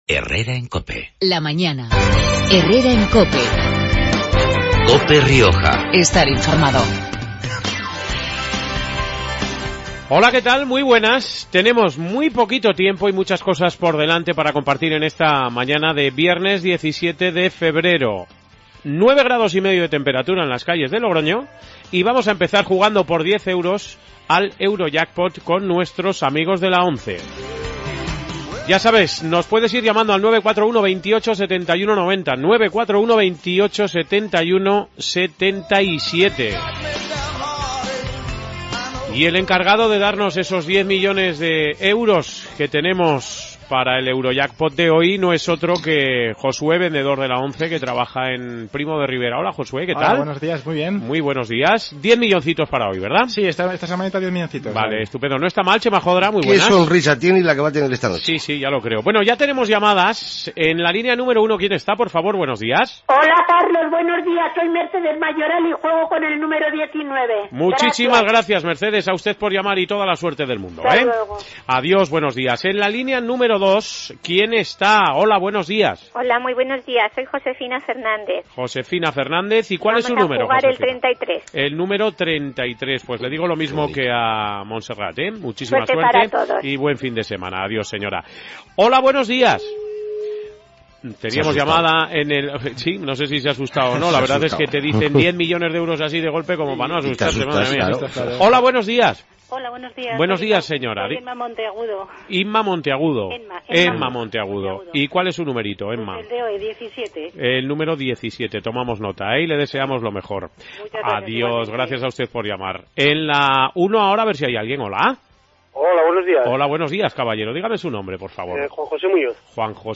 AUDIO: Programa regional de actualidad, entrevistas y entretenimiento. Hoy jugamos con la ONCE al Eurojackpot por 10 millones de euros.